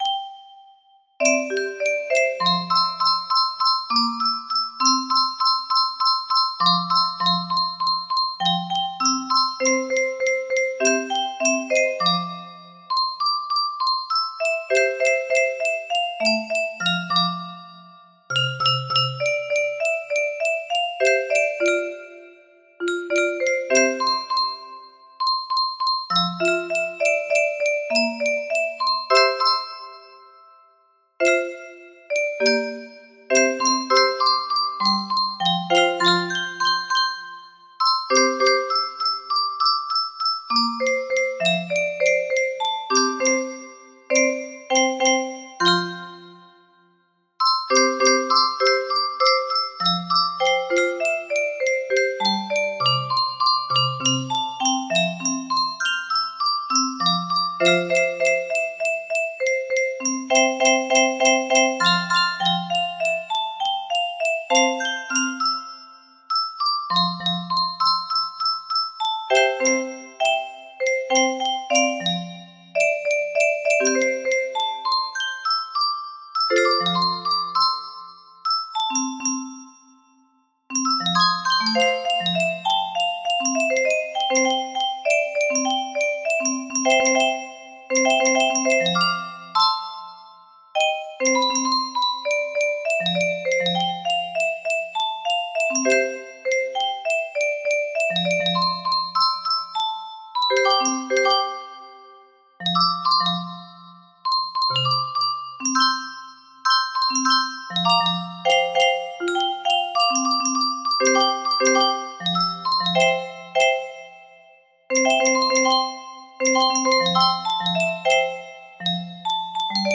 Unknown Artist - Untitled music box melody
Full range 60
Imported from MIDI from imported midi file (1).mid